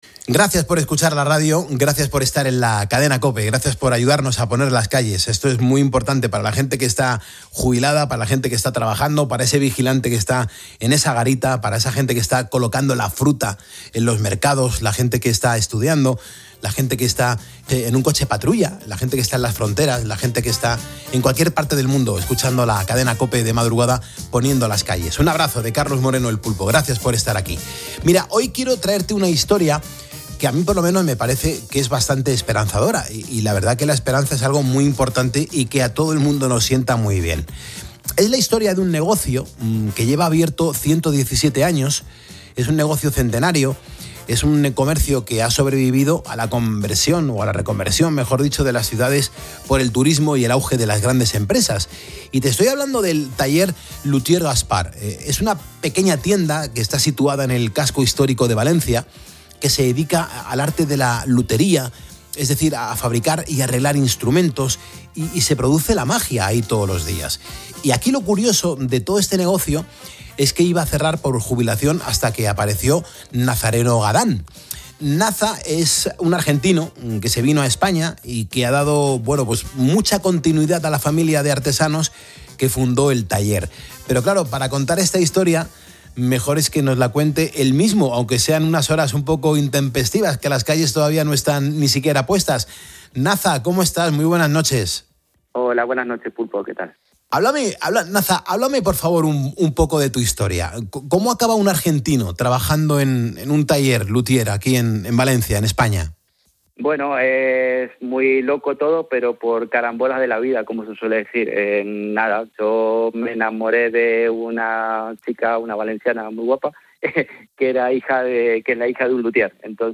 12:21 4 min lectura 22:29 min escucha Descargar Facebook Twitter Whatsapp Telegram Enviar por email Copiar enlace La esperanza tiene, a veces, acento argentino .